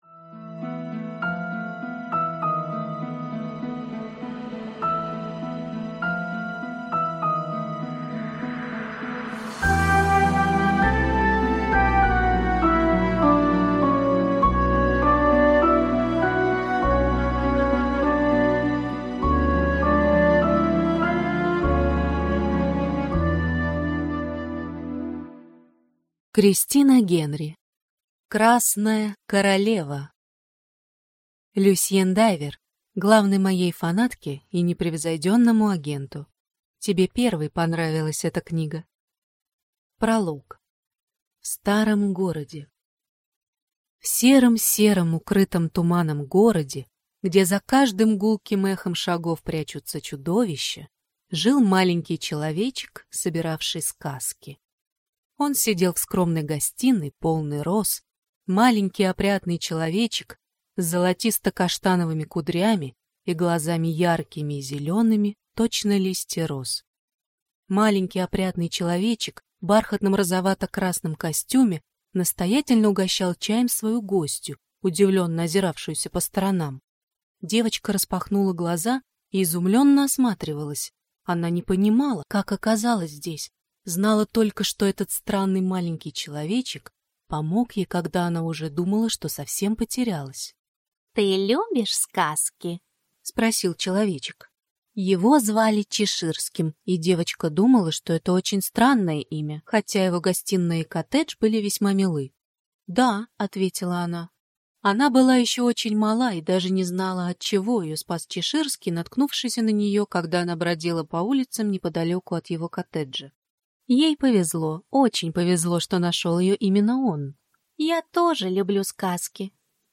Аудиокнига Красная королева | Библиотека аудиокниг